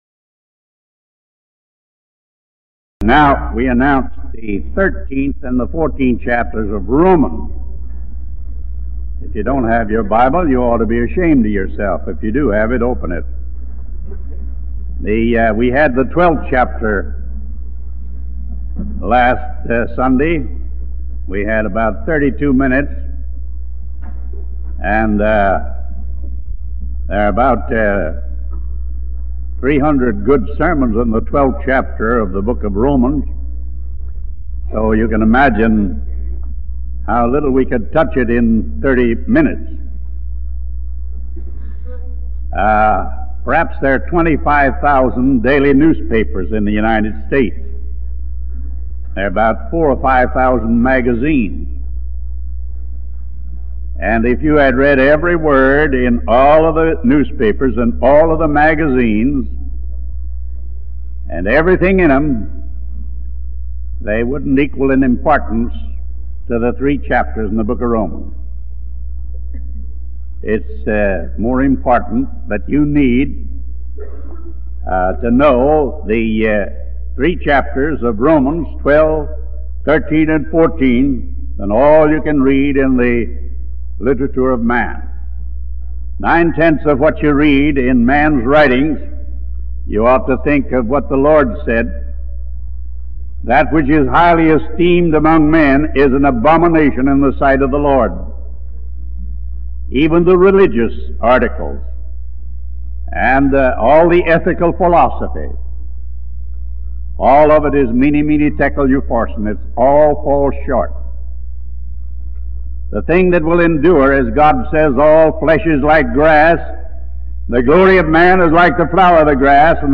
a sermon from the 1940’s or 1950’s on Romans 13 and 14